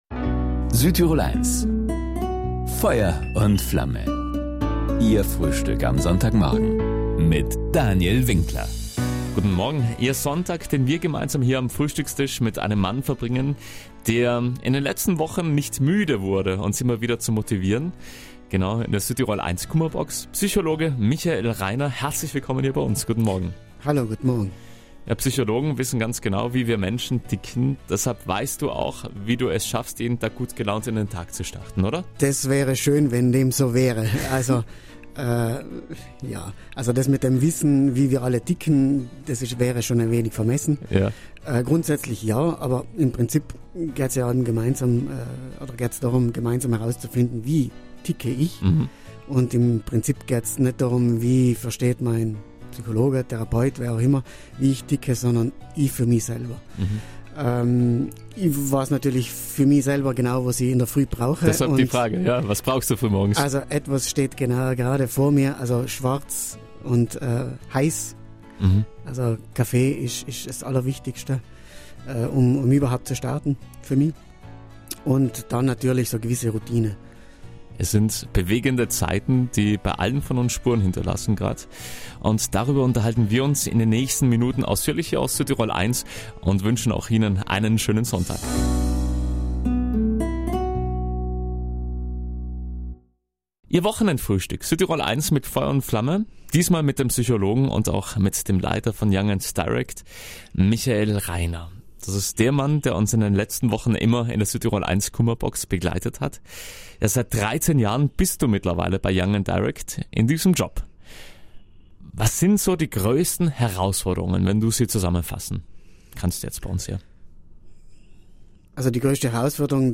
Ein Gespräch über Herausforderungen, die uns alle angehen.